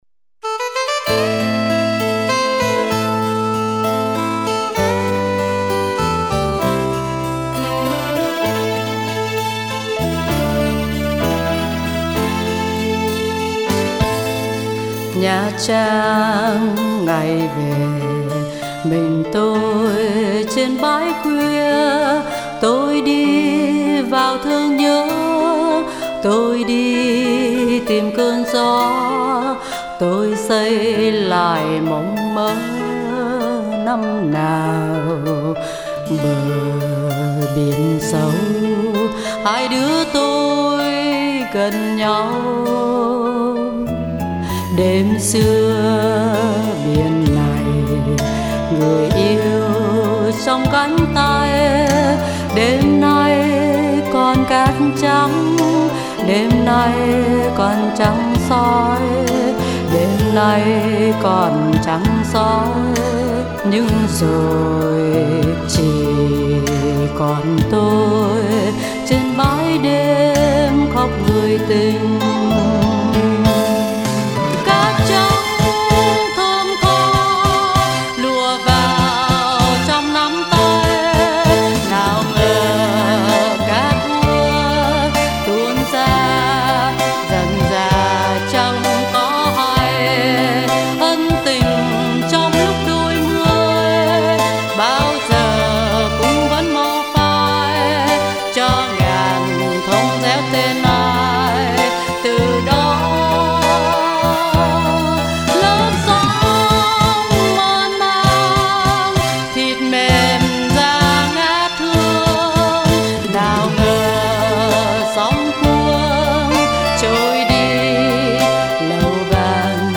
Giọng hát dịu ngọt, mềm mại, ấm áp và truyền cảm.